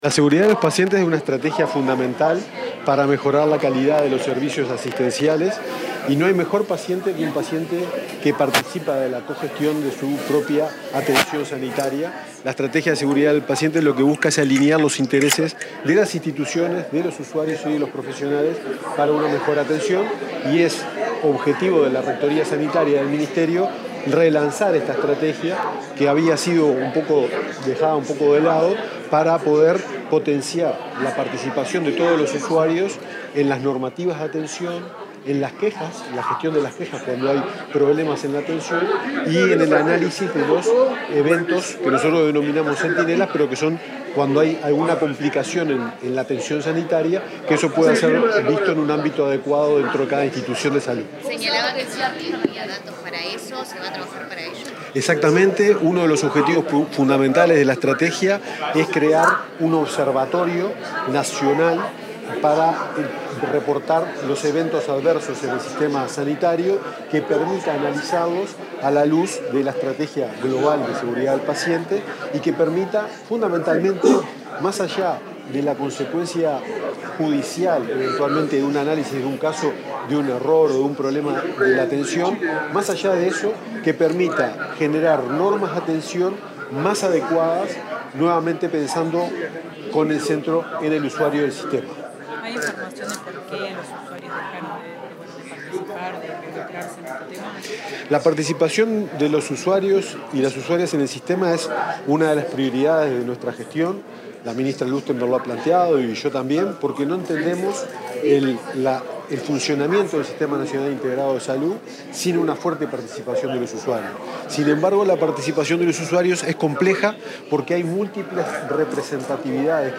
Declaraciones del subsecretario del MSP, Leonel Briozzo
Declaraciones del subsecretario del MSP, Leonel Briozzo 06/08/2025 Compartir Facebook X Copiar enlace WhatsApp LinkedIn Tras participar en el relanzamiento de la Estrategia Nacional de Seguridad del Paciente, el subsecretario del Ministerio de Salud Pública (MSP), Leonel Briozzo, dialogó con la prensa.